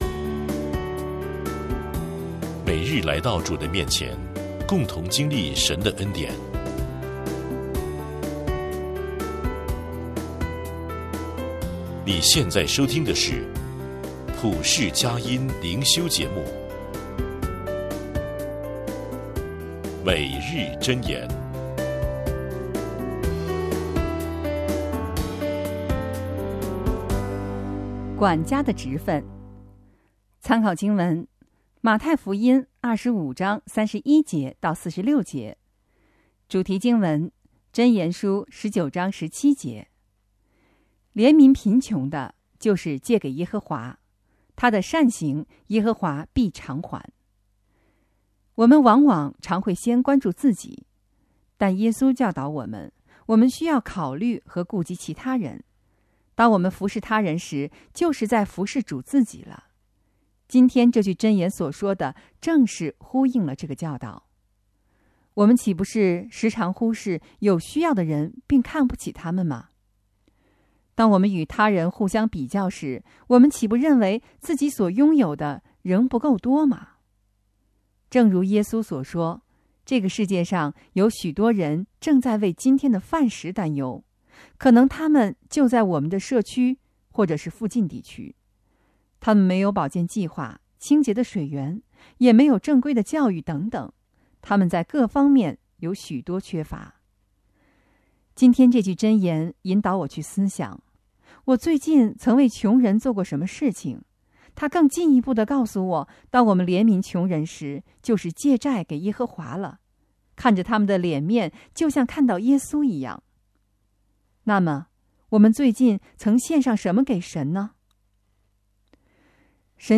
诵读